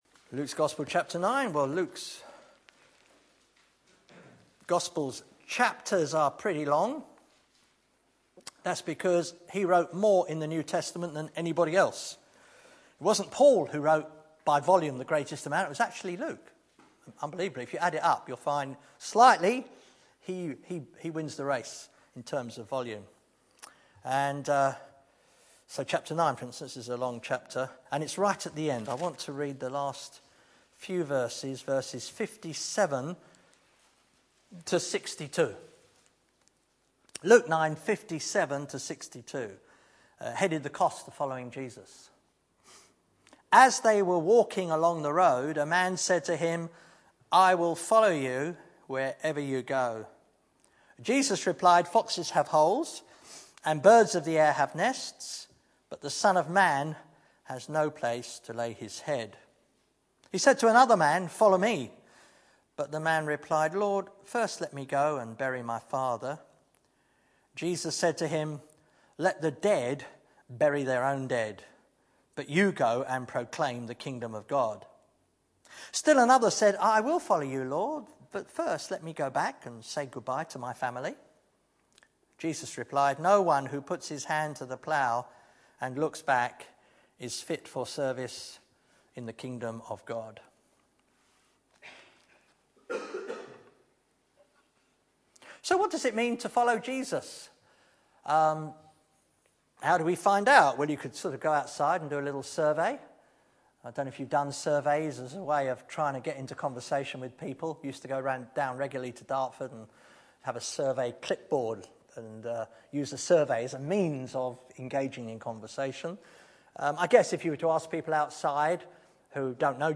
Back to Sermons What does it mean to follow Jesus?